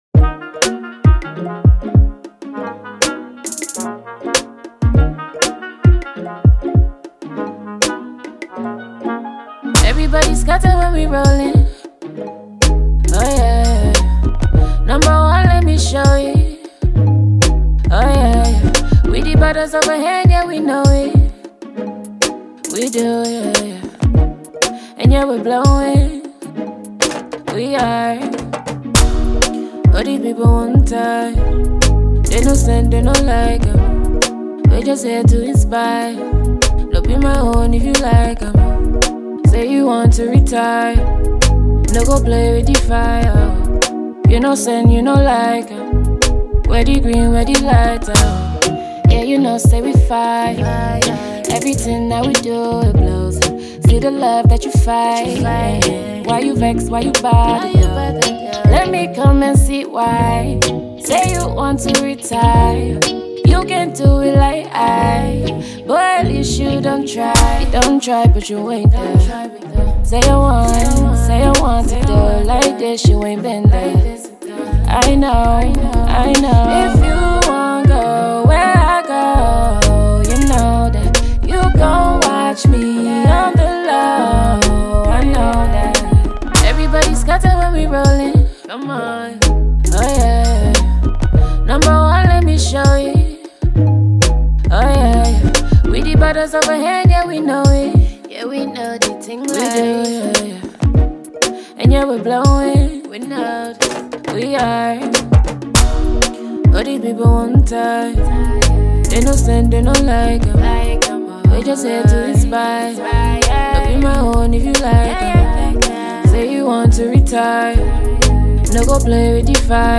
British Nigerian sultry singer